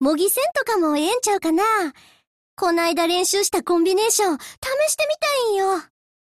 文件 49 KB =={{int:filedesc}}== 游戏语音 =={{int:license-header}}== {{fairuse}} 1